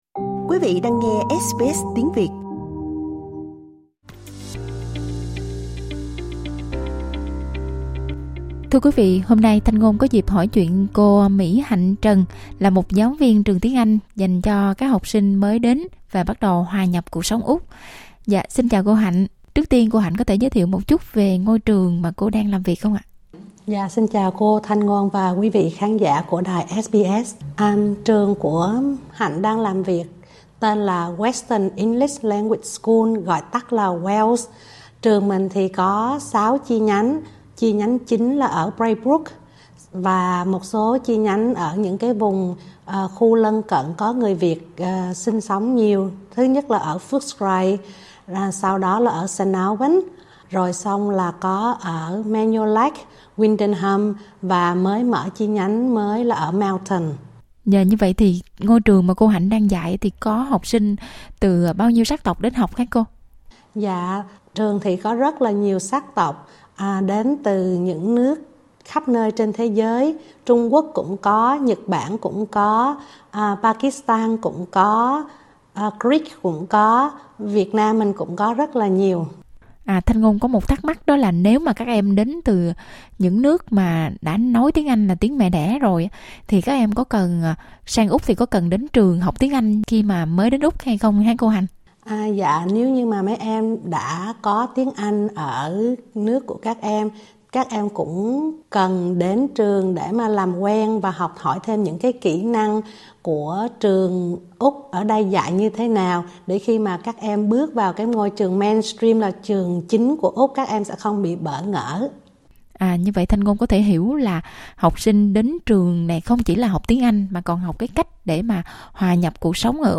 SBS Việt ngữ